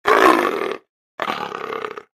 sounds / monsters / cat / c2_die_3.ogg
c2_die_3.ogg